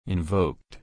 /ɪnˈvoʊk/